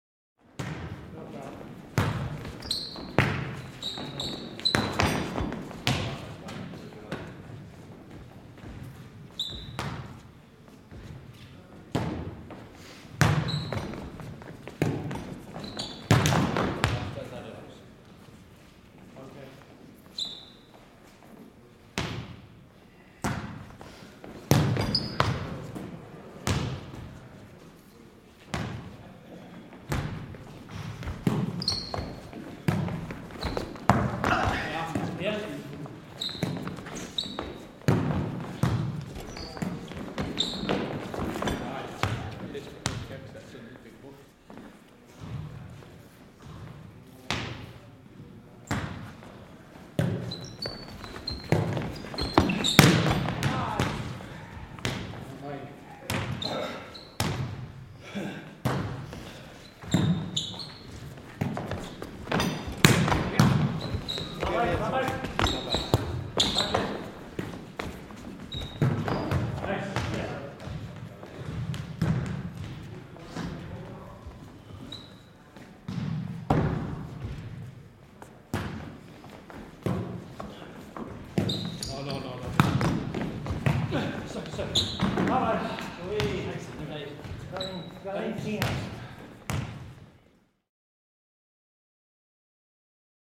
Звуки волейбола
Звуки волейбольного матча в гимнастическом зале